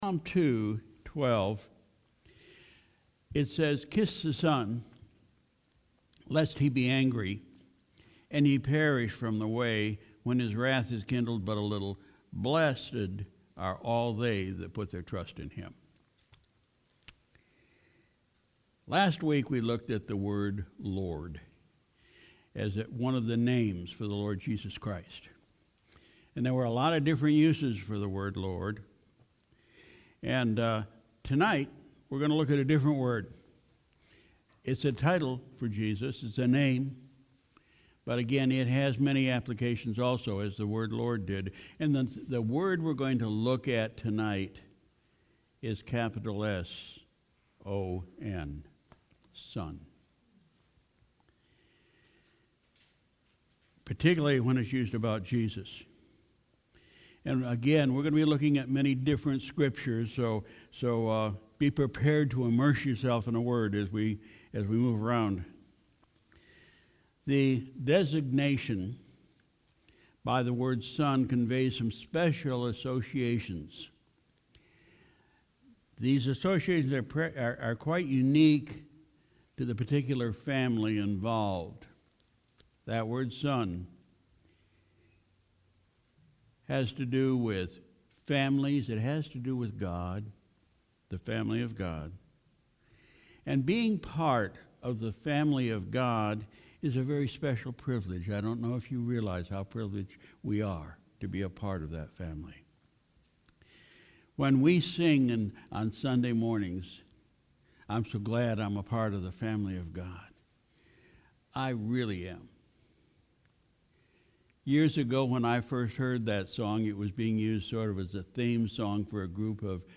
January 27, 2019 Evening Service Key Word: Son